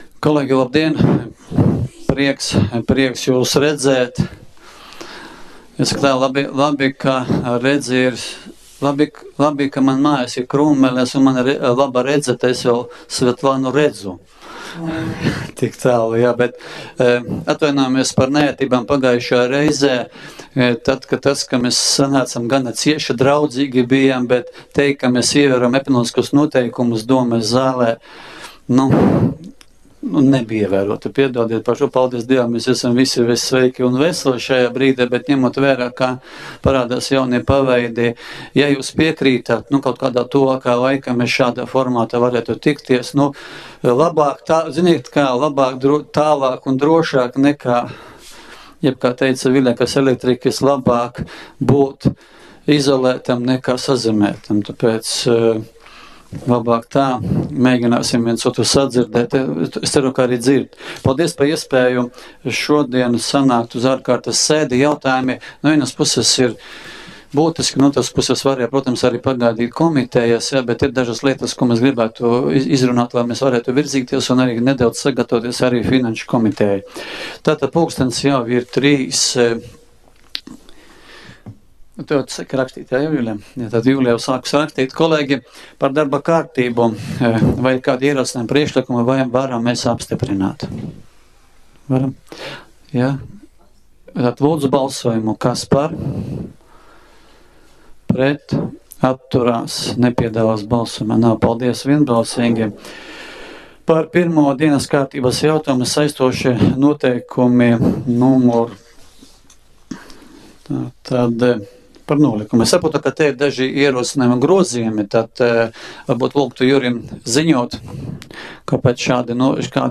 13. jūlija domes ārkārtas sēde